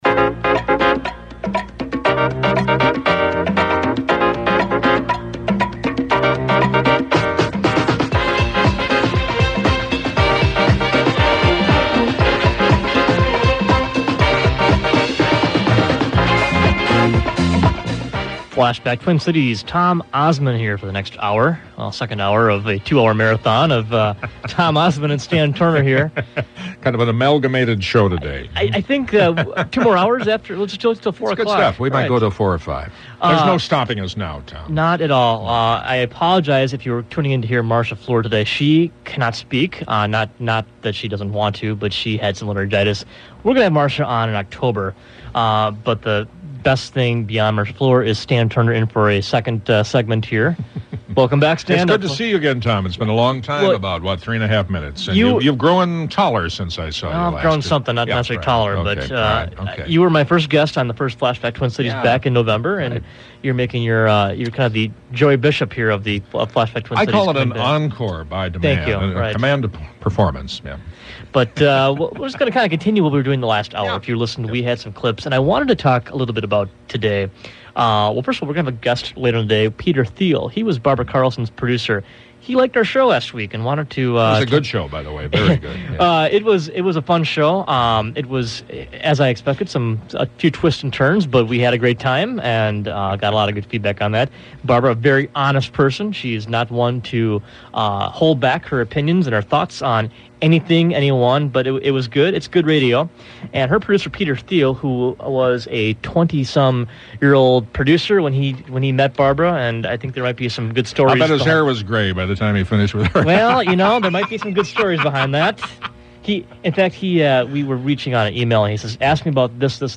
Tune in as these two long time broadcasting men talk about their careers.